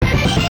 jingles-hit_05.ogg